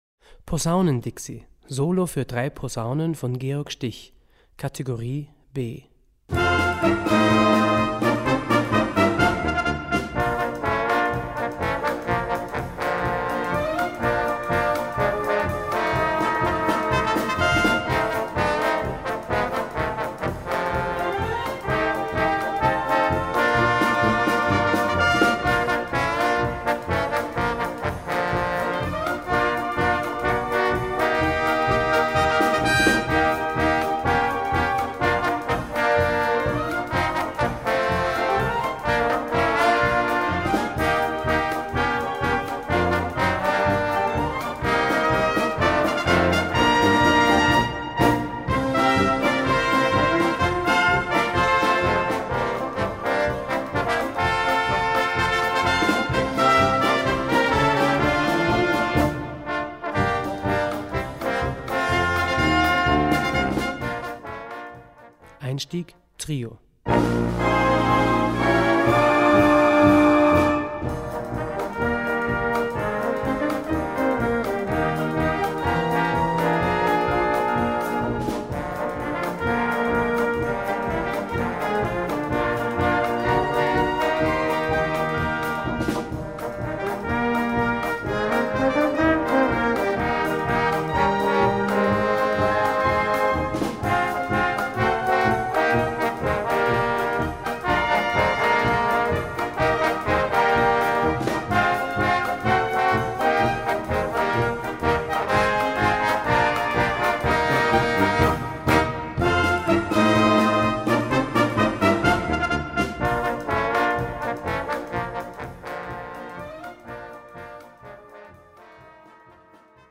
Solostück für 3 Posaunen und Blasorchester Schwierigkeit
Besetzung: Blasorchester